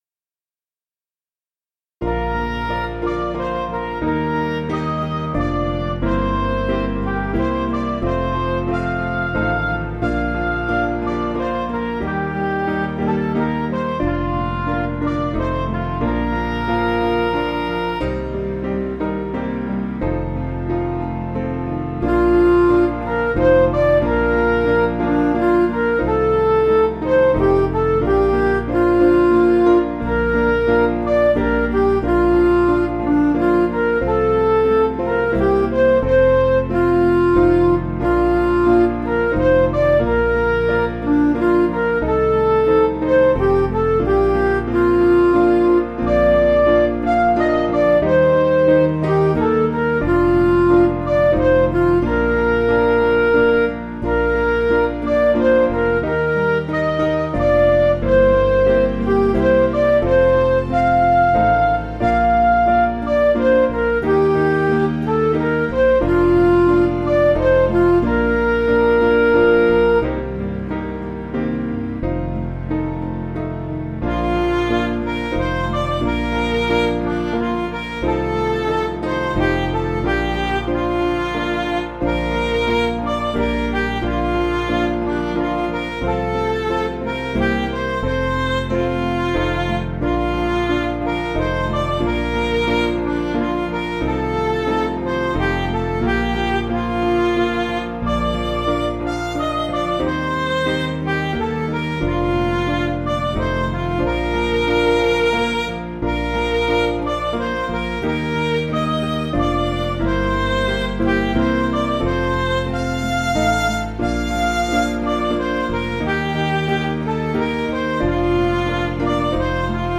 Piano & Instrumental
(CM)   5/Bb
Midi